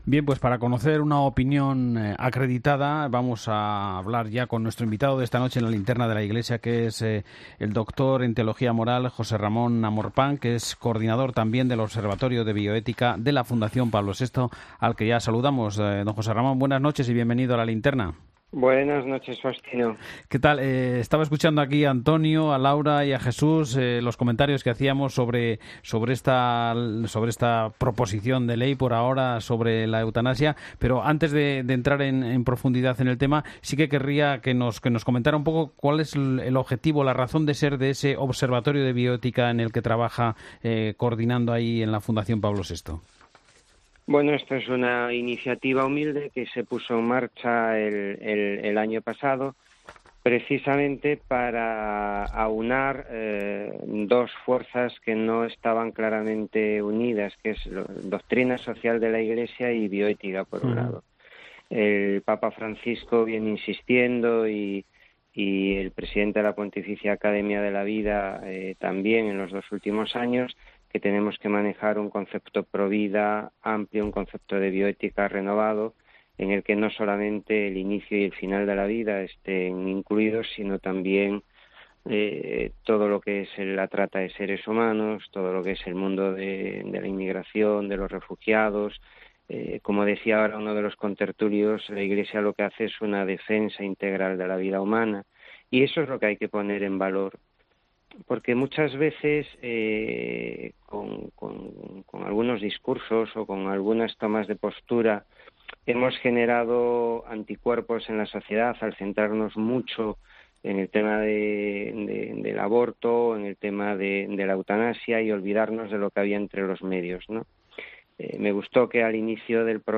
Para ello, por los micrófonos de 'La Linterna de la Iglesia' ha pasado una persona autorizada para hablar de toda esta delicada cuestión.